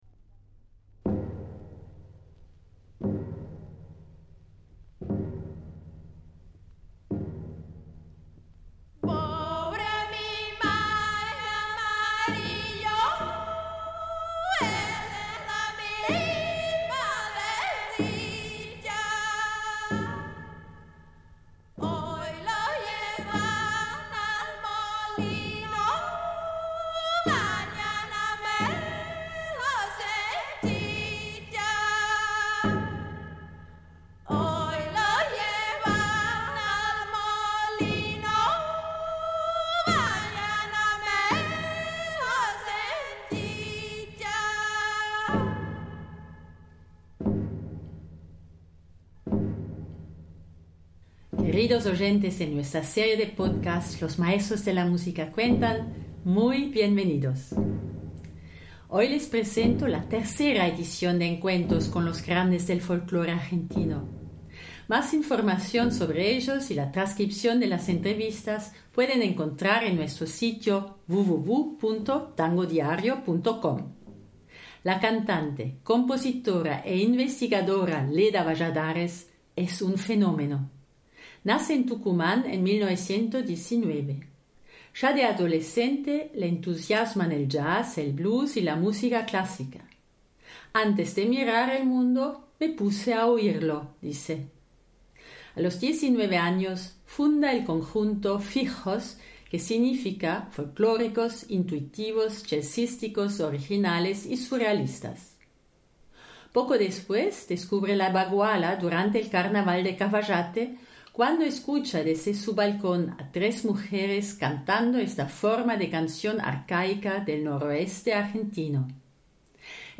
Escuchen ustedes mismos lo que esta gran cantante, compositora e investigadora, fallecida en julio de 2012 a los 92 años, me dijo en su salón mientras tomámos una taza de té y unas deliciosas galletitas.